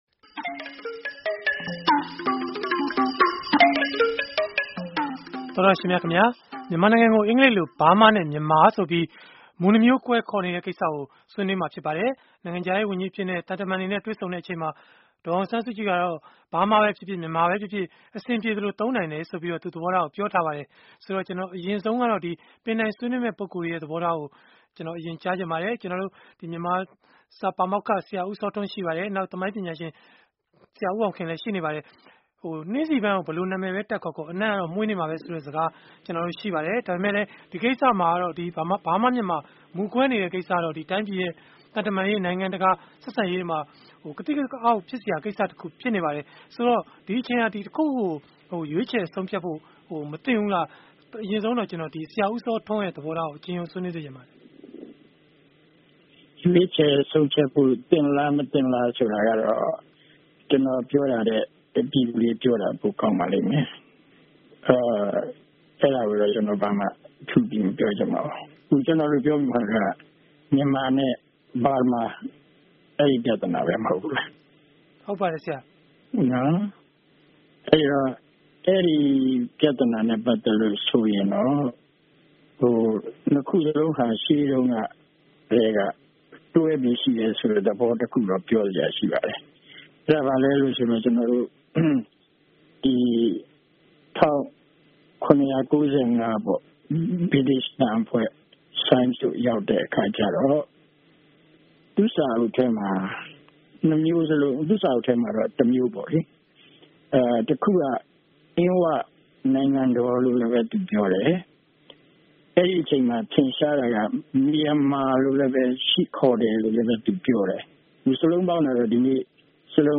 မြန်မာနိုင်ငံကို အင်္ဂလိပ်လို Burma နဲ့ Myanmar အခေါ်အဝေါ် ၂ မျိုး ကွဲနေတဲ့ အထဲမှာ ဘယ်အမည်က တိုင်းပြည်အတွက် အသင့်တော်ဆုံးဖြစ်မလဲ ဆိုတာကို အင်္ဂါနေ့ည တိုက်ရိုက်လေလှိုင်း အစီအစဉ်မှာ ဆွေးနွေးထားပါတယ်။